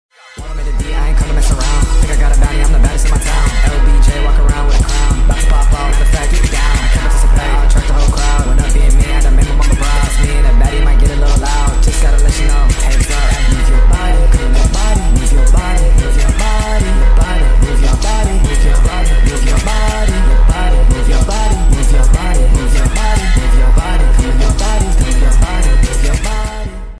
sped 27 seconds 603 Downloads